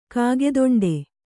♪ kāgedoṇḍe